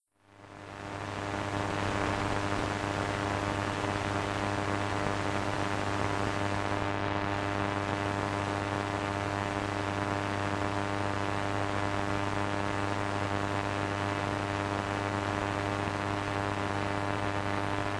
radionoise.wav